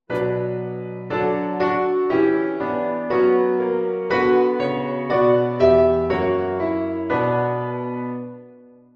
tegenbeweging